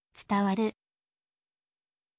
tsutawaru